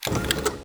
ht-locomotive-open.ogg